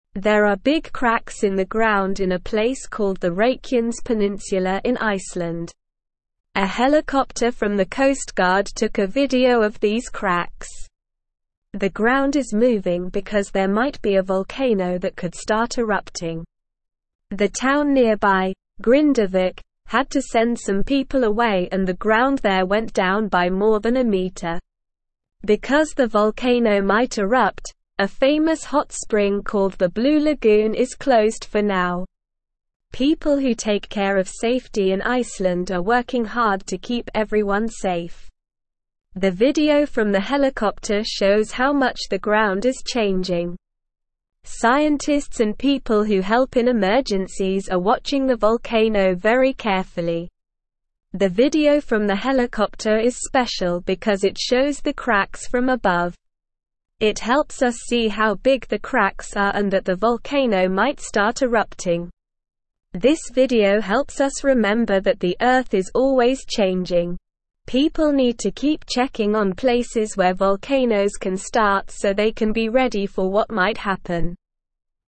Slow
English-Newsroom-Lower-Intermediate-SLOW-Reading-Cracks-in-Ground-Volcano-Waking-Up-in-Iceland.mp3